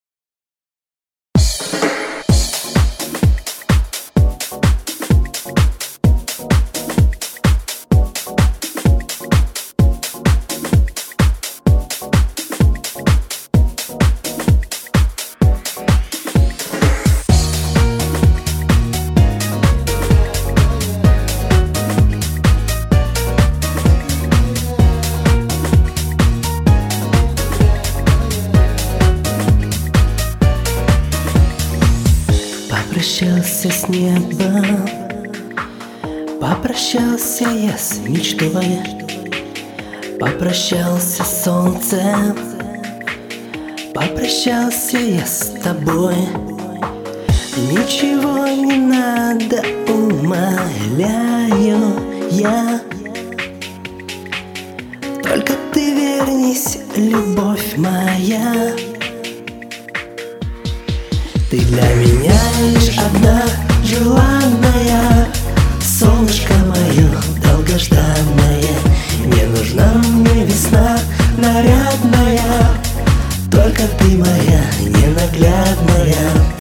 Умничка, спела супер, пробрала до дрожи!!!
голосок чистый но особенного ничего не вижу...